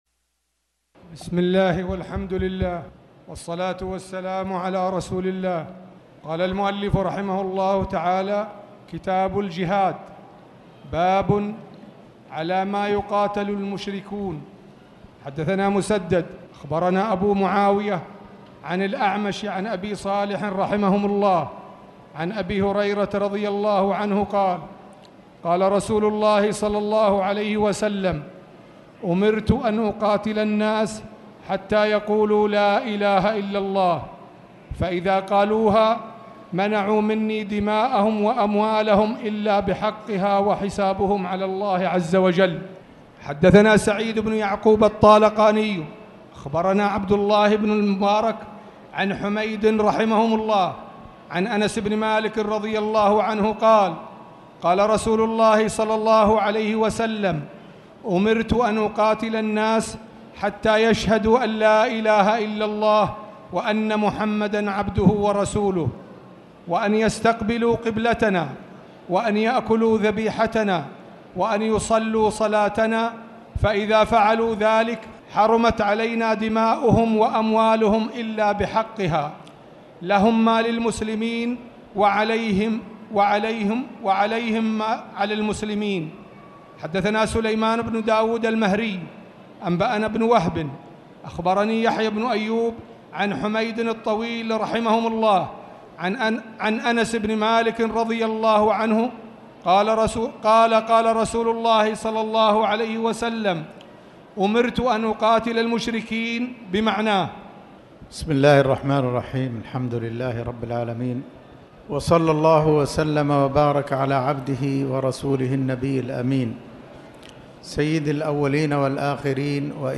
تاريخ النشر ١٢ صفر ١٤٣٩ هـ المكان: المسجد الحرام الشيخ